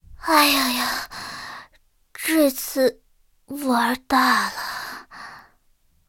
M4A3E2小飞象被击毁语音.OGG